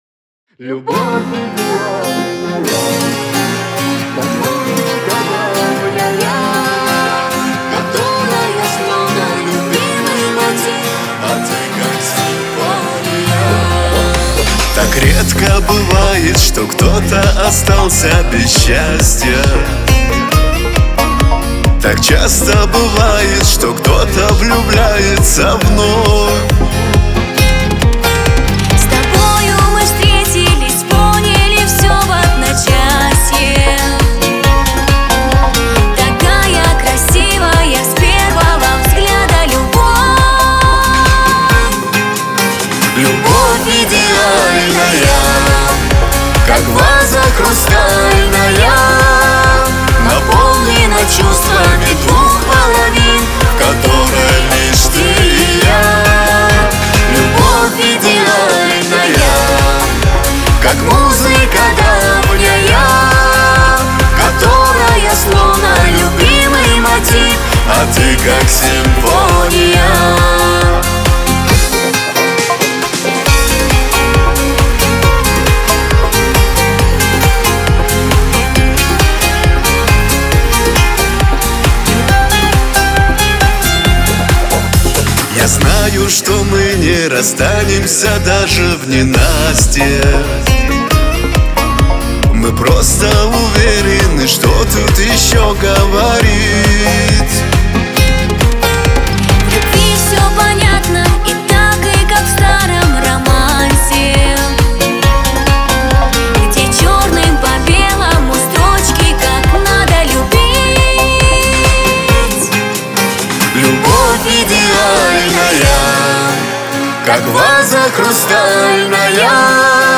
дуэт
Кавказ – поп